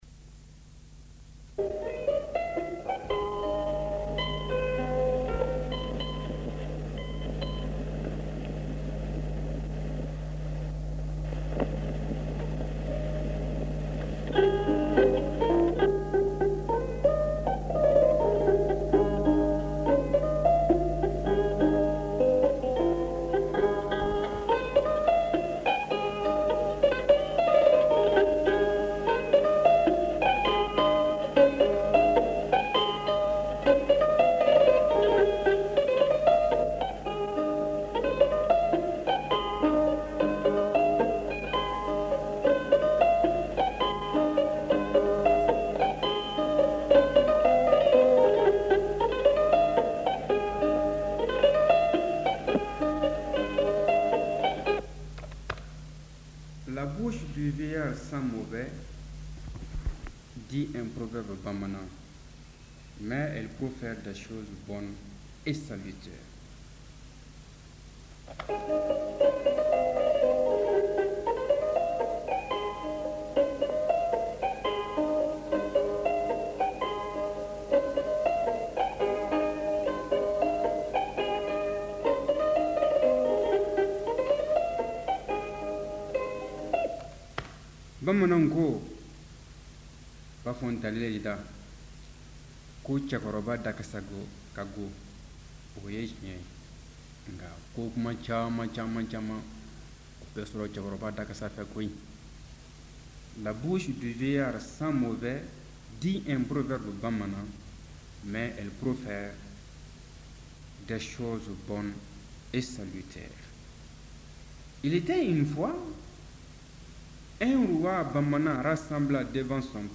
Les rènes du royaume : Conte malien · OmekaS By DataCup · Omekas - Mali